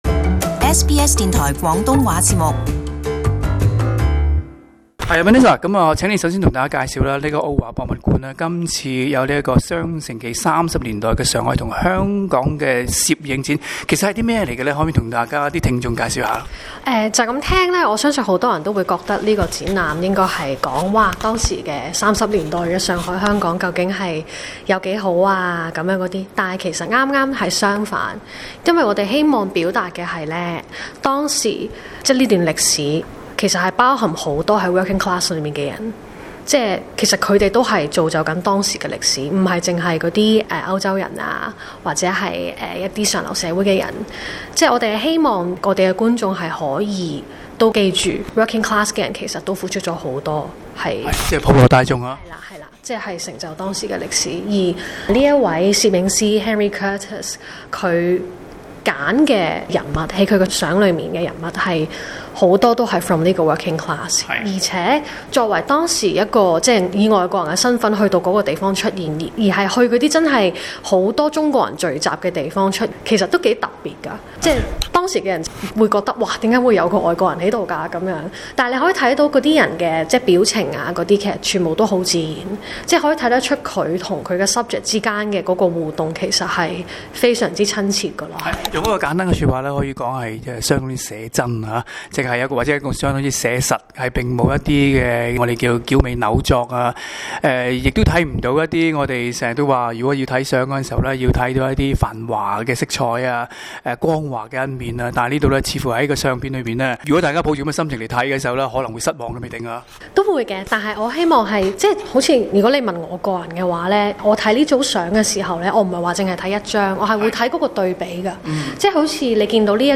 【社区专访】澳华博物馆双城记香港及上海摄影展介绍